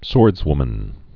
(sôrdzwmən)